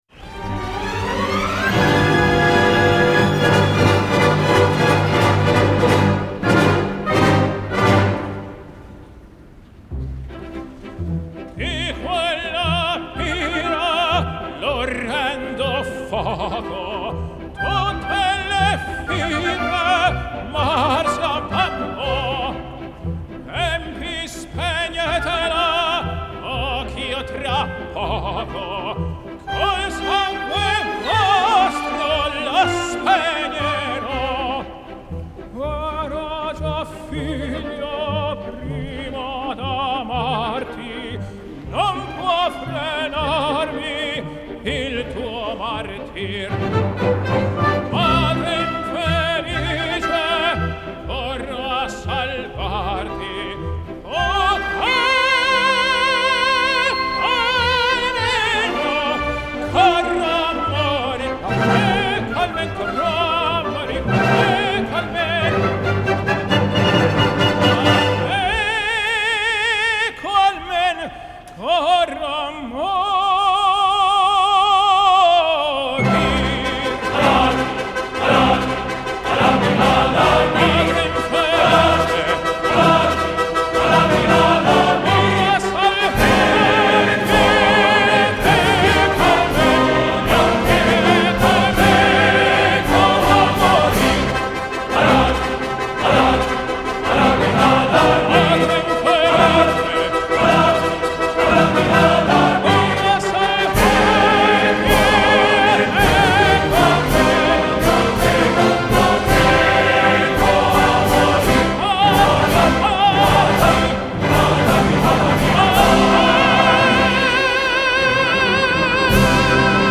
Genre : Classical
Live At Central Park, New York